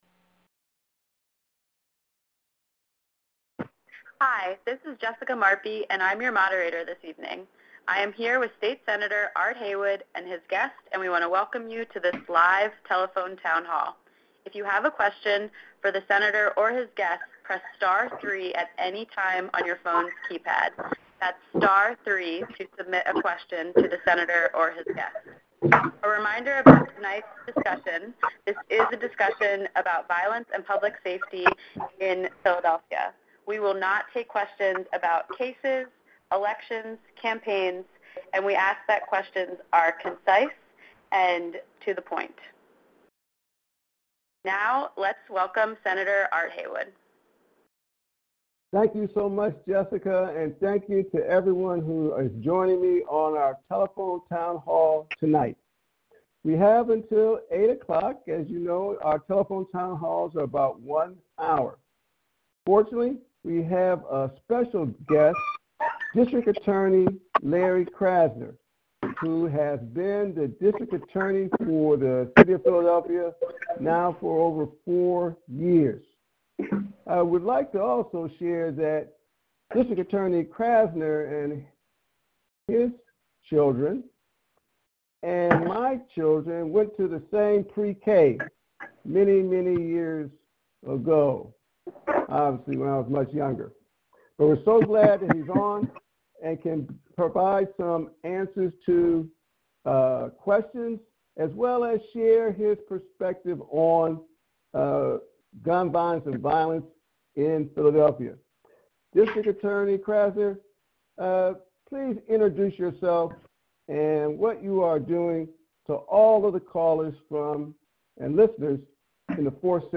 Senator Haywood hosted a Telephone Town Hall on October 24, 2024 as part of his Dignity for All Series.